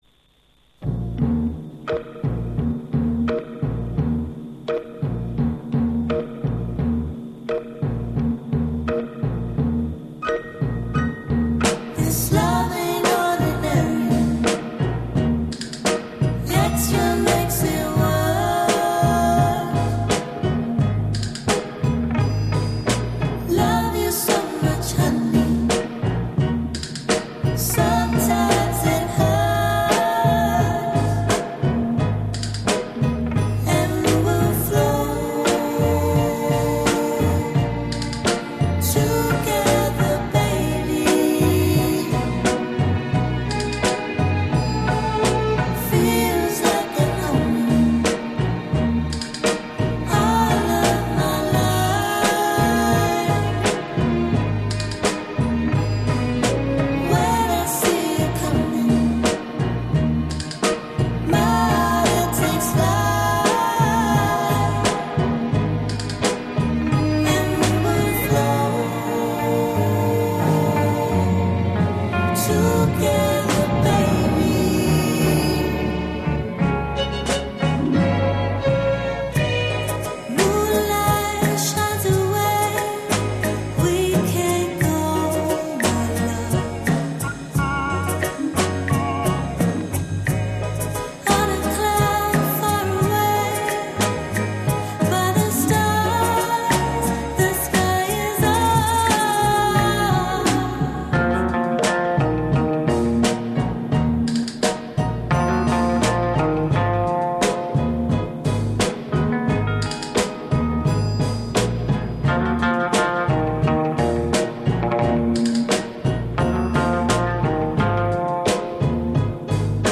smooth yet also funky grooves to keep you warm and mellow